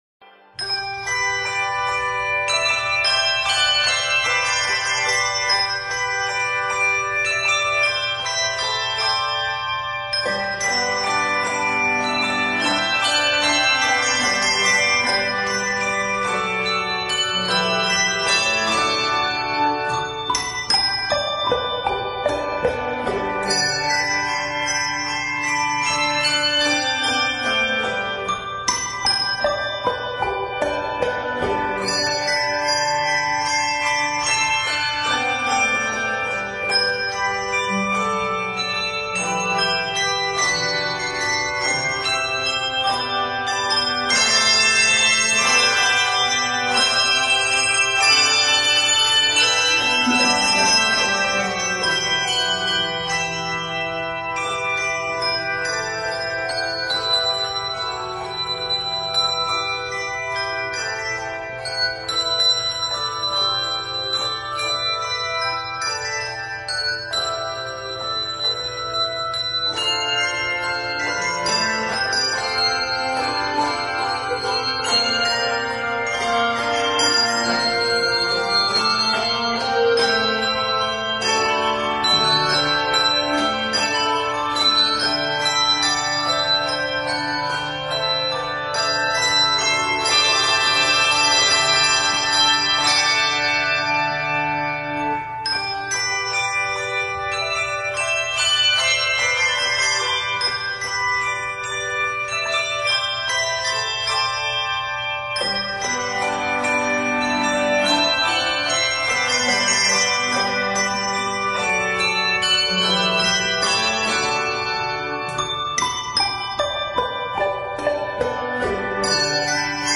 bells